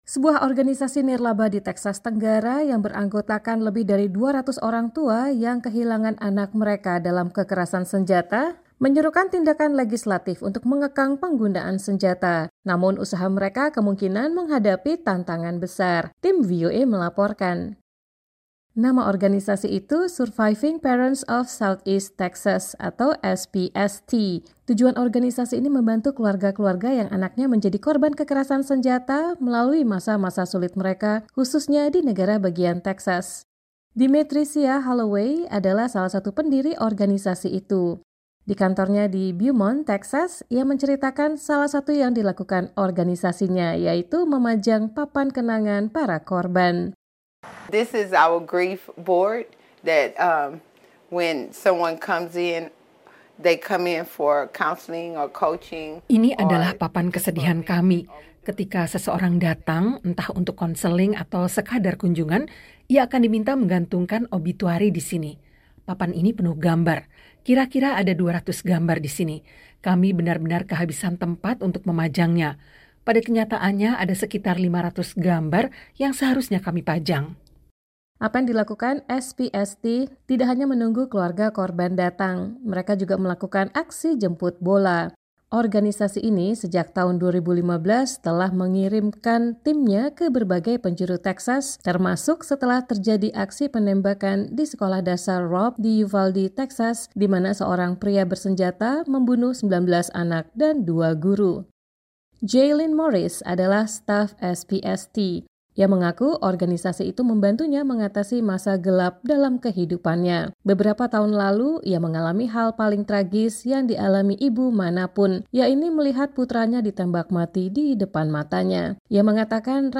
Tim VOA melaporkan.